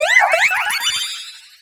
Cri de Strassie dans Pokémon X et Y.